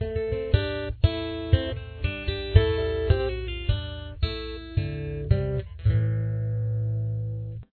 • Key Of: D
• Instruments: Acoustic Guitar